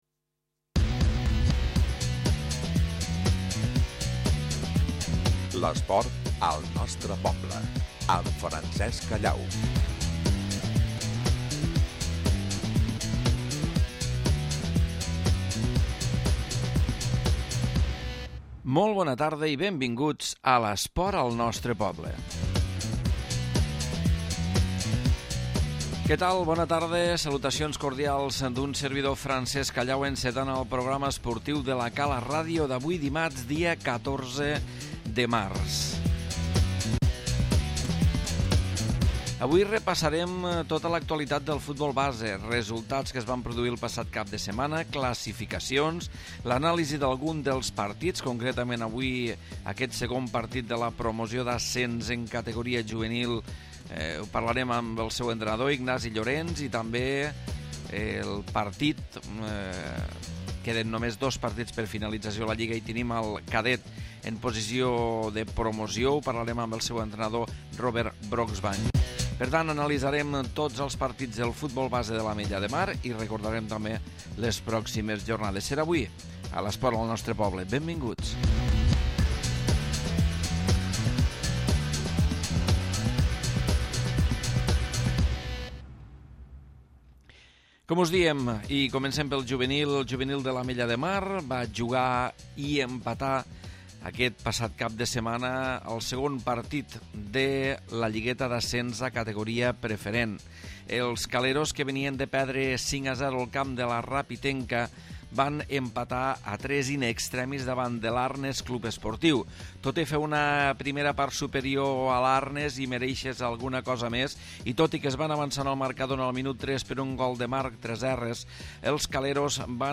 Repàs a l'actualitat del futbol base de l'Ametlla de Mar i entrevistes